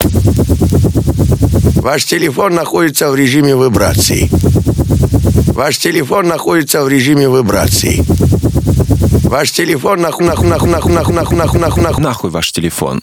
vibracija.mp3